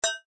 4_plink_3.ogg